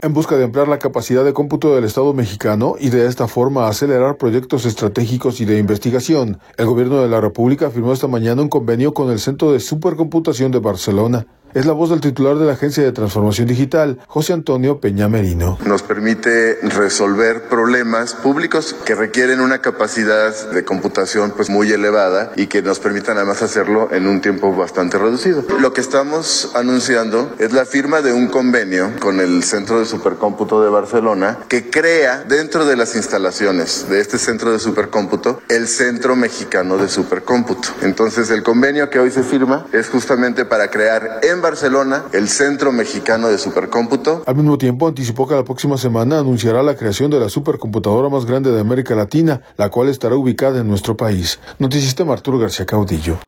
audio En busca de ampliar la capacidad de cómputo del Estado mexicano, y de esta forma acelerar proyectos estratégicos y de investigación, el Gobierno de la República firmó esta mañana un convenio con el Centro de Supercomputación de Barcelona. Es la voz del titular de la Agencia de Transformación Digital, José Antonio Peña Merino.